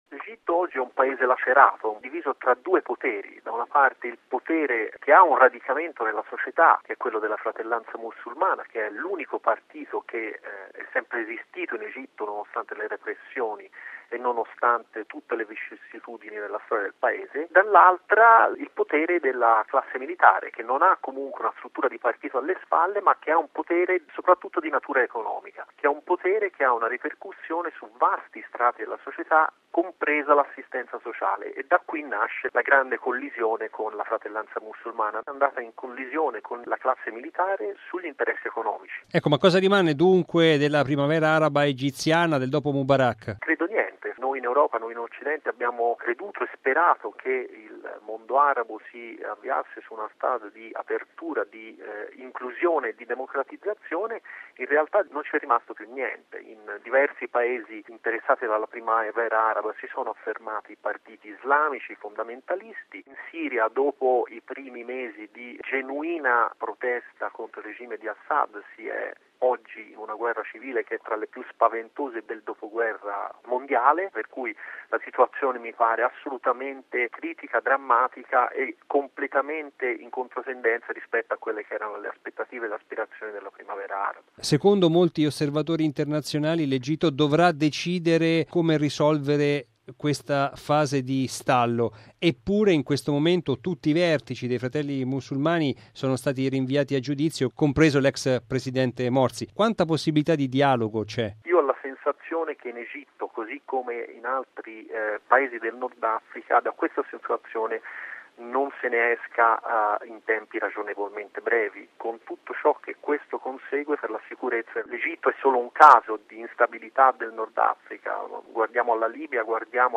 Radiogiornale del 15/11/2013 - Radio Vaticana